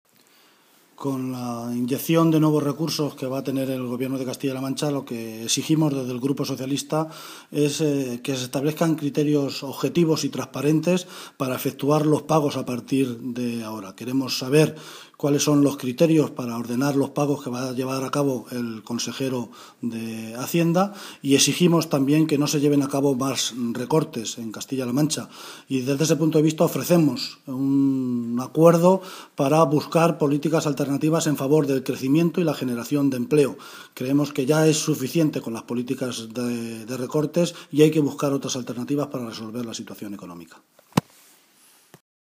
El portavoz del PSOE en las Cortes de Castilla-La Mancha, José Luis Martínez Guijarro, ha comparecido esta tarde en rueda de prensa para analizar el acuerdo del Consejo de Ministros que autoriza a Castilla-La Mancha a endeudarse a largo plazo en más de 1.500 millones de euros.
Cortes de audio de la rueda de prensa